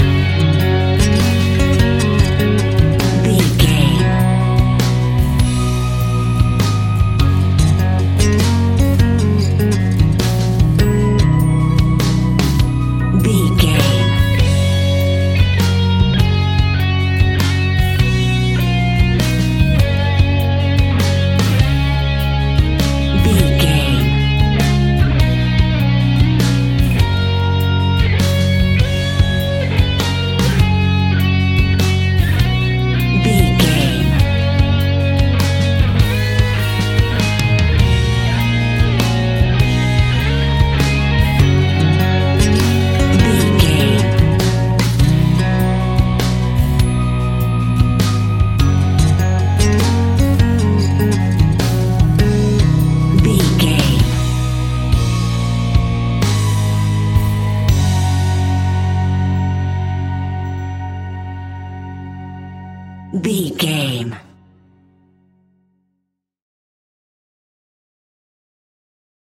Ionian/Major
indie pop
energetic
uplifting
instrumentals
upbeat
groovy
guitars
bass
drums
piano
organ